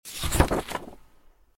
SFX_Book_Open.mp3